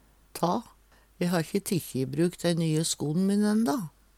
DIALEKTORD PÅ NORMERT NORSK ta ta Infinitiv Presens Preteritum Perfektum ta tæk tok tikkji Eksempel på bruk E ha`kje tikkji i bruk dei nye sko`n mine enda.